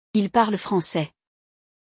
É simples: a conjugação de -ENT é muda, silenciada.
O final -ENT nos verbos da terceira pessoa do plural é mudo e não deve ser pronunciado.